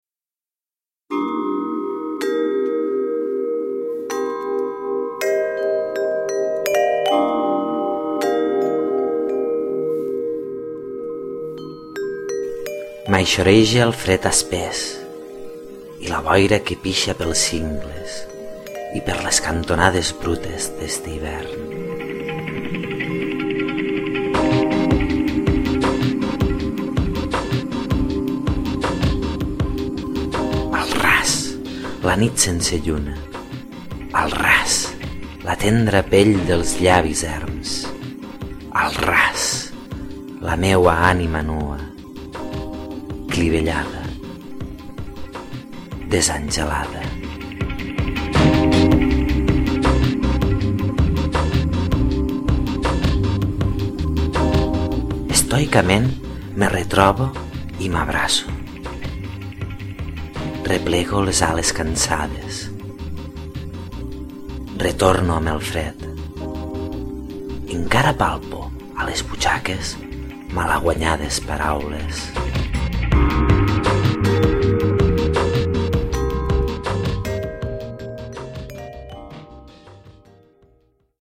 La música del podcast és de Revolution Void, i la cançó és la titulada Nebulous Notions.